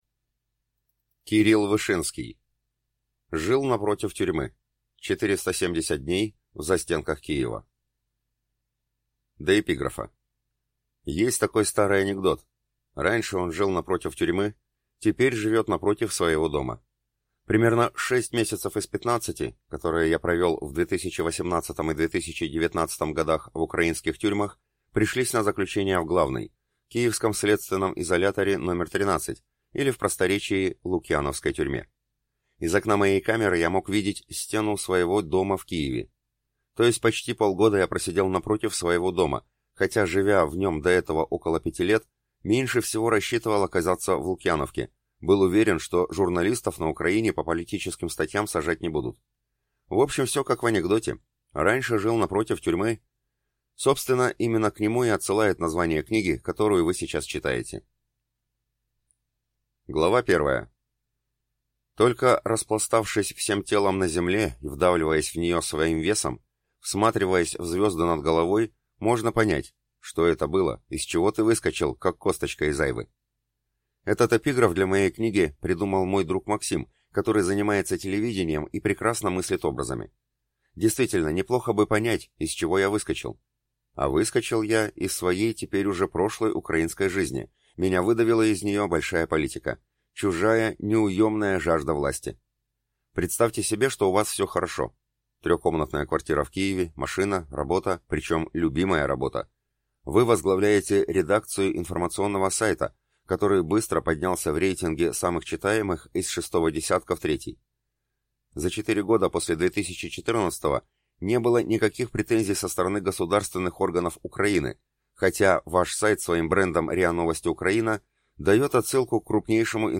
Аудиокнига «Жил напротив тюрьмы…». 470 дней в застенках Киева | Библиотека аудиокниг